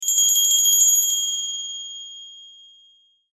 Cell Phone Notification Sounds in MP3 Format
Christmas Jingle
ChristmasBellJingle.mp3